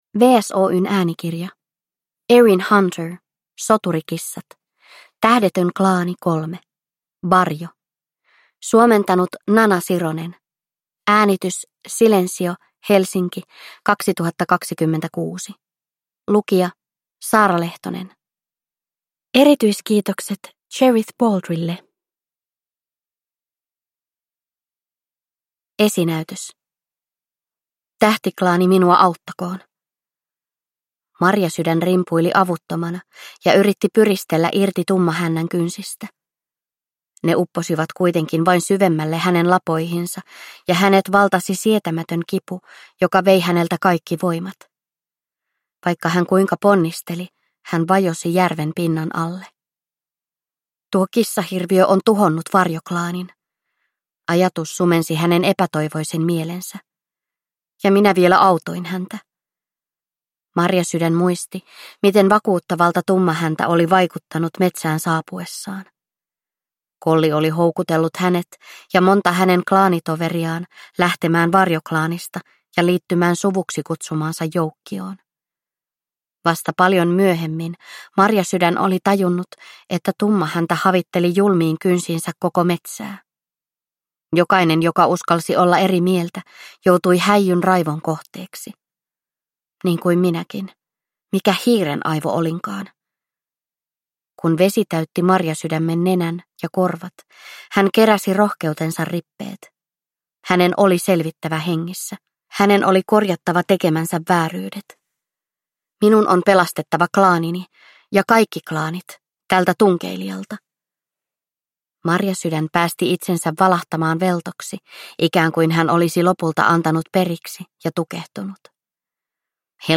Soturikissat: Tähdetön klaani 3: Varjo (ljudbok) av Erin Hunter